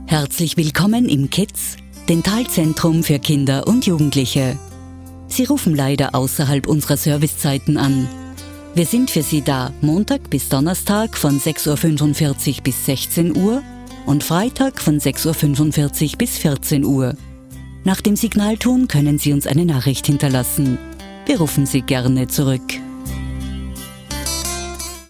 Die Stimme ist bekannt aus zahlreichen Werbeproduktionen, sehr variantenreich von seriös, entspannt erzählerisch bis frech, sinnlich, warm.
Sprechprobe: Sonstiges (Muttersprache):
She can sound deep and trustworthy but also very energetic.